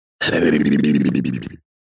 Звуки мультяшного голоса
Грубиян